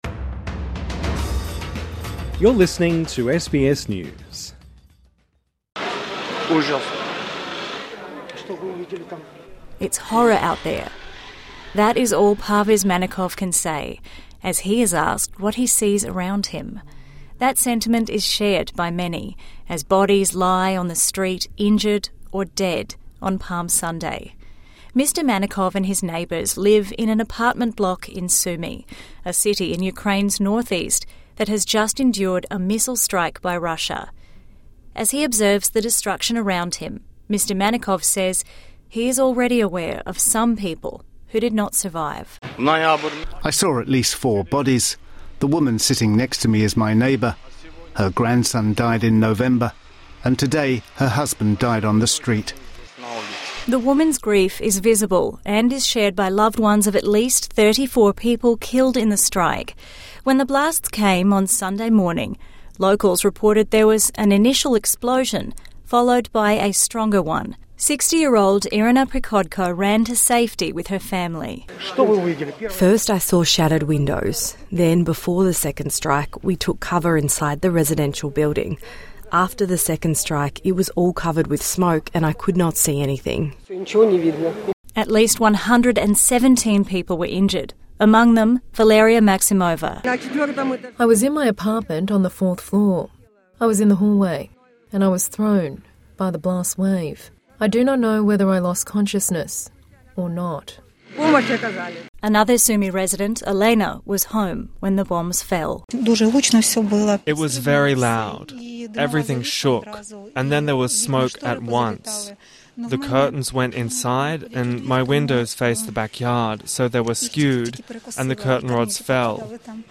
Ukrainian officials say Russian strikes killed at least 34 people in the northeastern city of Sumy [[soo-me]] in one of the deadliest attacks in months. The attack injured nearly 120 people and prompted President Volodymyr Zelenskyy to renew his calls for a tougher international response to Russia's aggression.